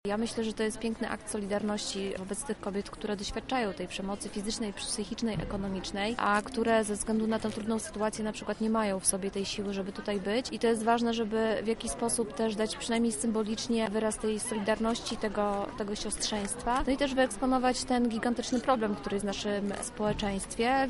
Odzyskać-noc -mówi jedna z uczestniczek